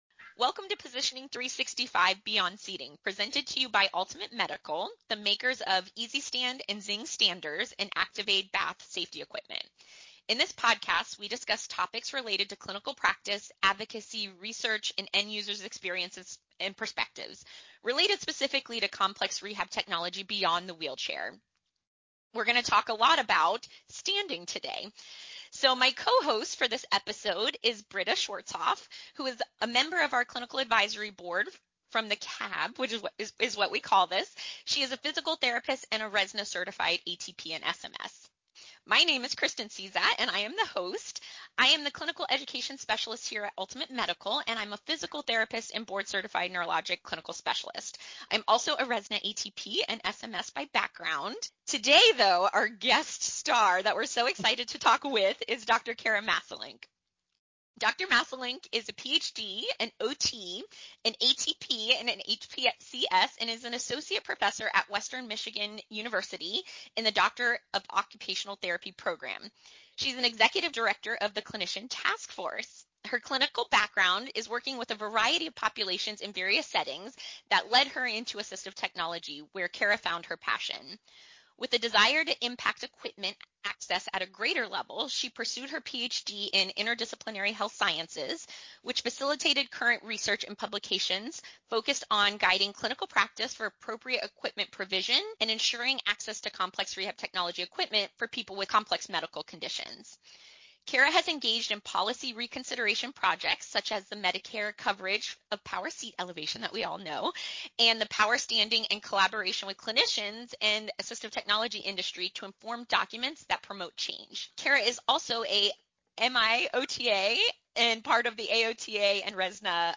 Together, they explore the collaborative position paper developed by RESNA and the Clinician Task Force on the use of supported standing devices. The conversation highlights how supported standing aligns with multiple domains of the International Classification of Functioning, Disability and Health (ICF) model